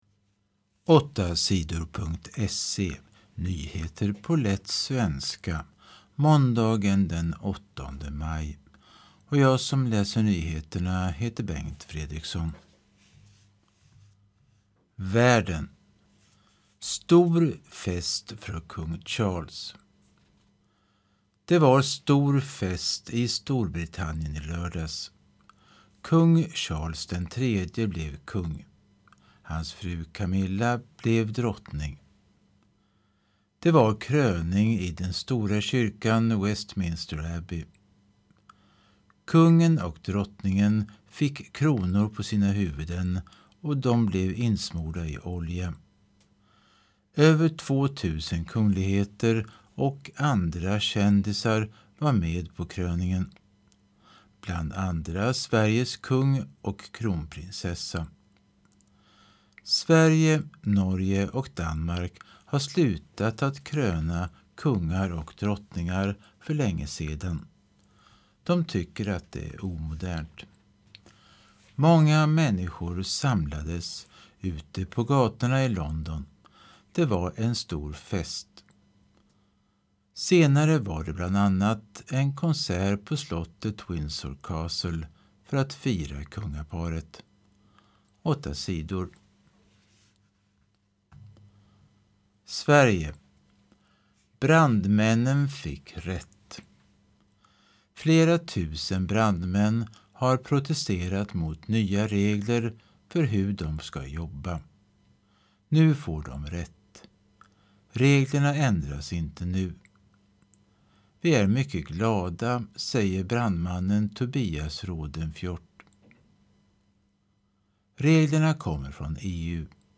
Lyssnar på nyheter från 2023-05-08.